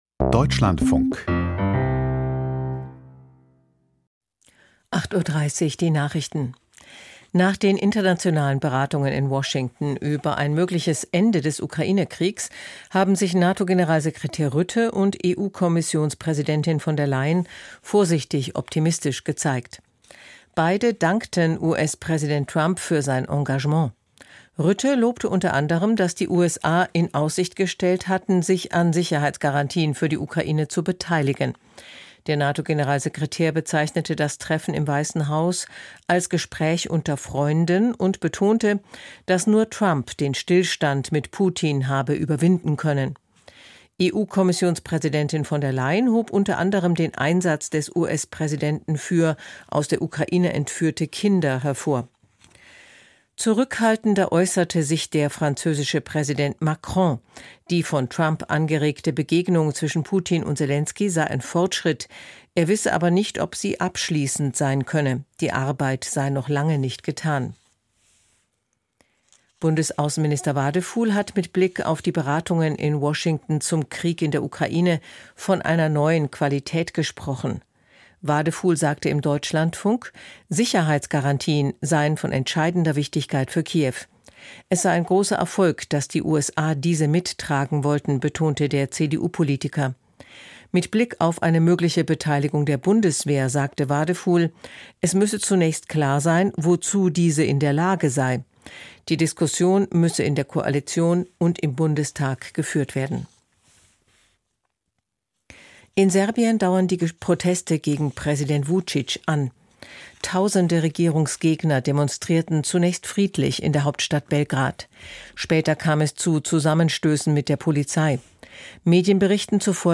Die Nachrichten vom 19.08.2025, 08:30 Uhr